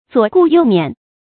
左顾右眄 zuǒ gù yòu miǎn
左顾右眄发音